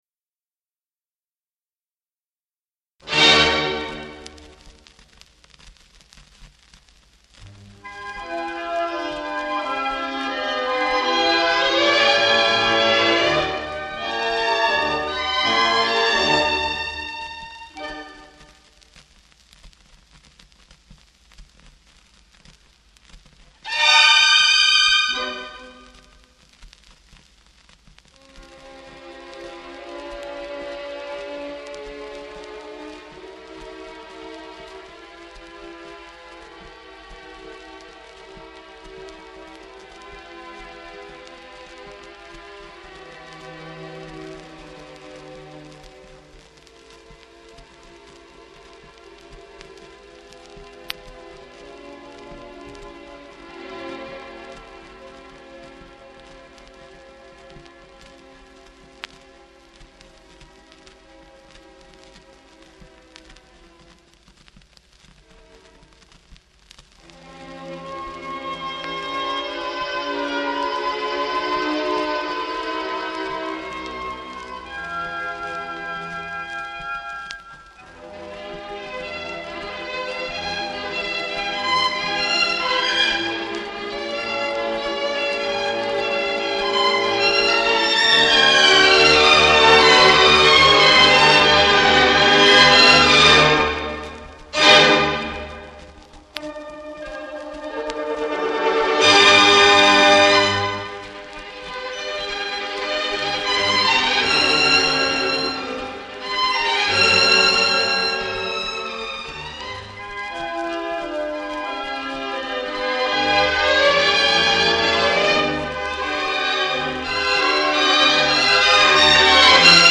The Original Orchestrations of Favorite Arias.